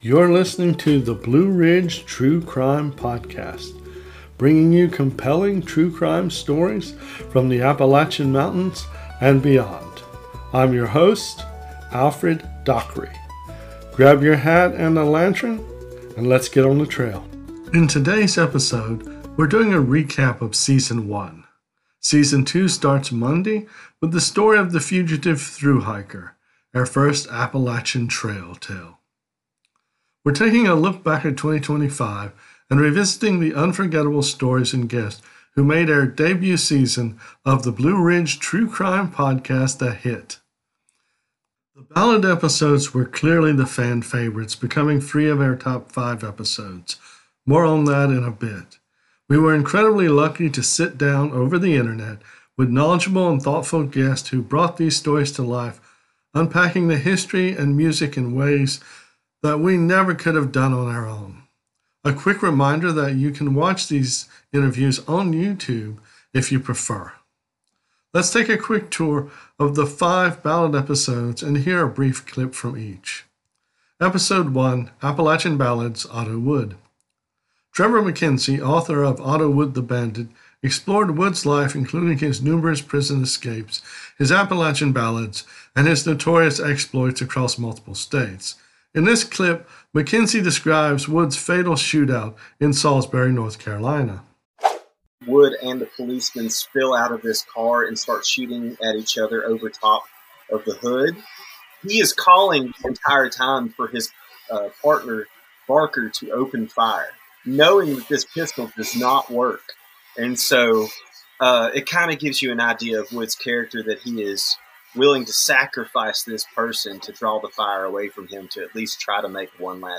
Featuring clips from each of our five cornerstone ballad episodes: